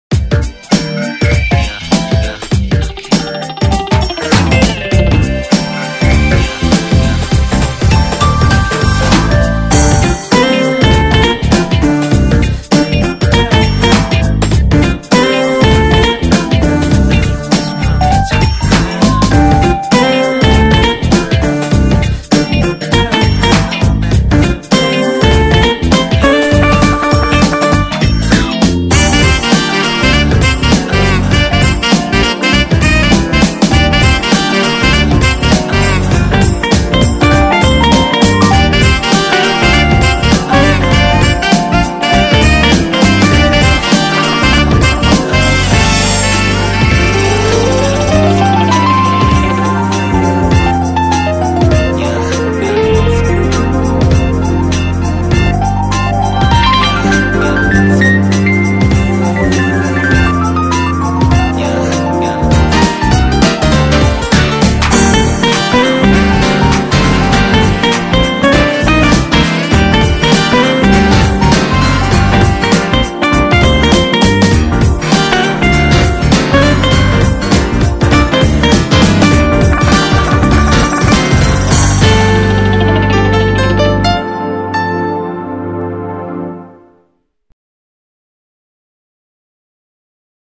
이곡 역시 일렉트로니카를 살짝 얹은 퓨젼느낌이 나는 곡입니다. 게임표시상에는 JAZZ POP 이라 써있네요.